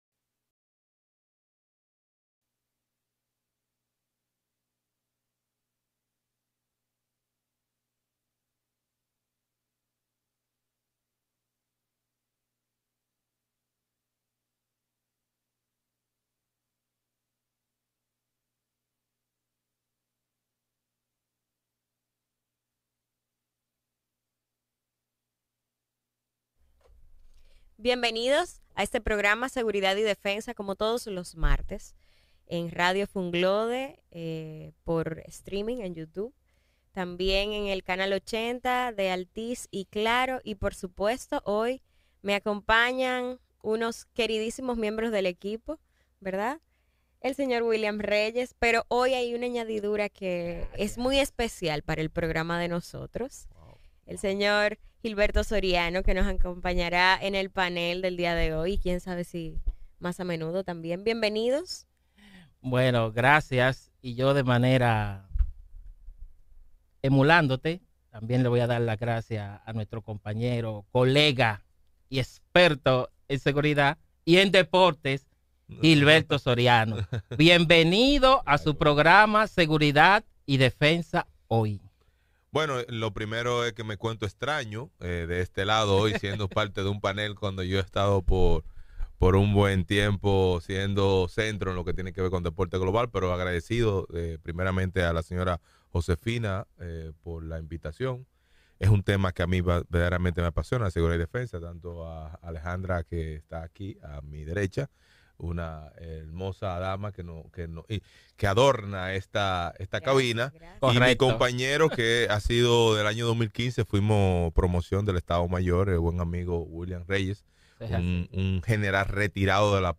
En esta entrevista, hablaremos sobre la importancia de las fuerzas armadas en el mantenimiento del orden público y la seguridad ciudadana en República Dominicana. Entre los temas que abordaremos en esta conversación, se encuentran las políticas públicas de seguridad ciudadana en el país, la importancia de la cooperación internacional para combatir la delincuencia, la labor de las fuerzas armadas en la lucha contra el narcotráfico y la protección de la soberanía nacional, y mucho más.